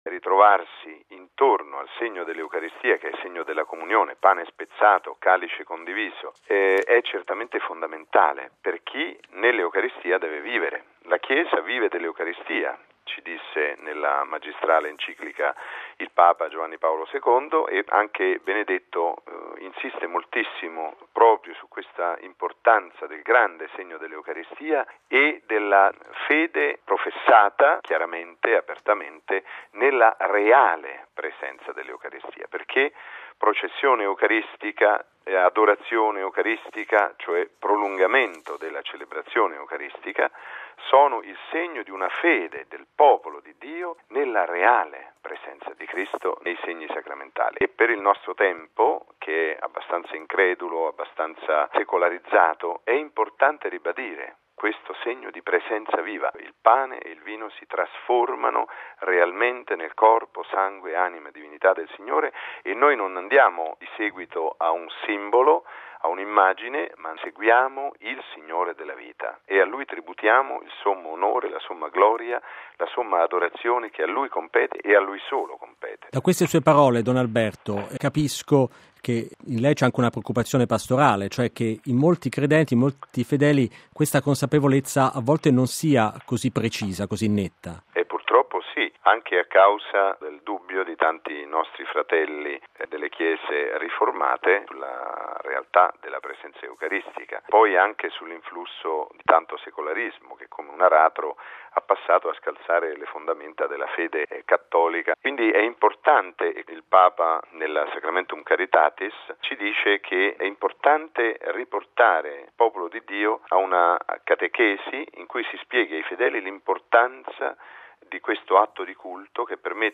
Oggi in Italia e in diversi Paesi si celebra la solennità del Corpus Domini.